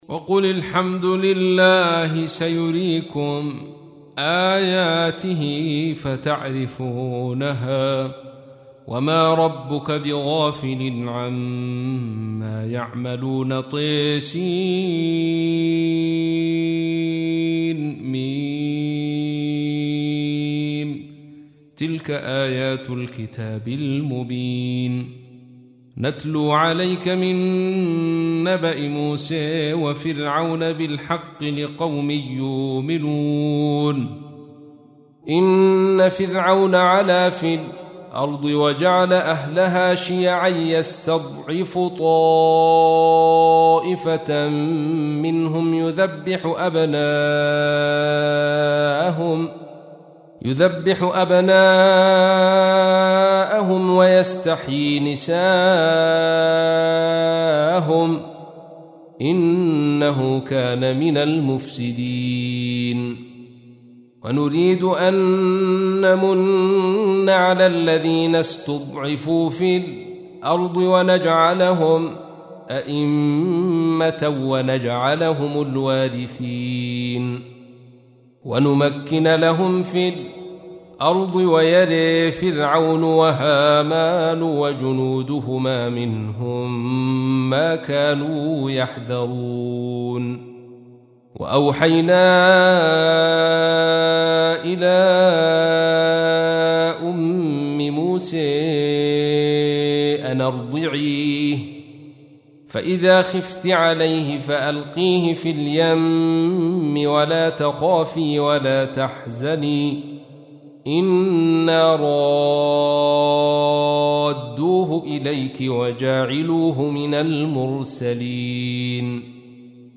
تحميل : 28. سورة القصص / القارئ عبد الرشيد صوفي / القرآن الكريم / موقع يا حسين